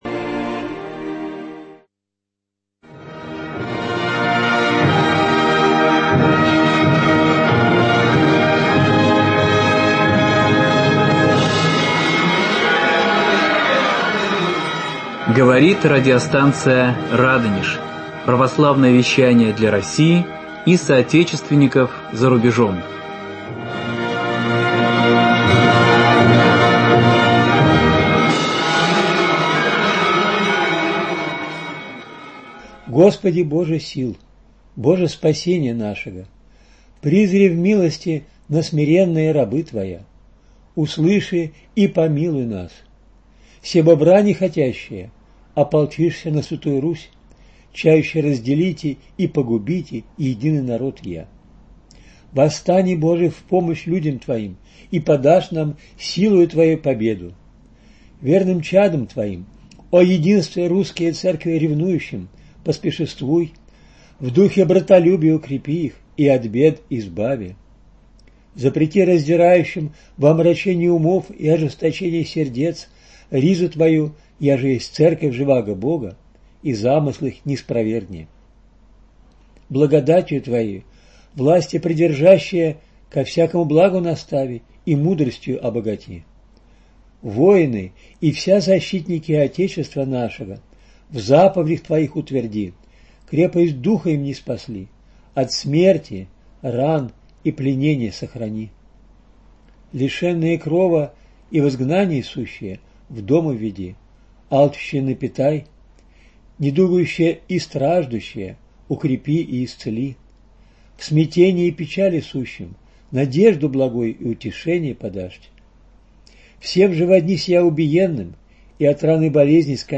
Чтение и объяснение евангельского рассказа о Закхее